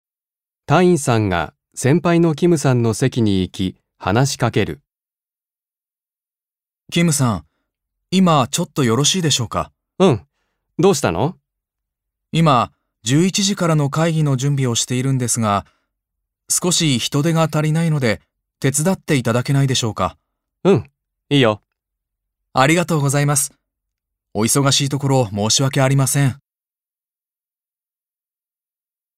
1. 会話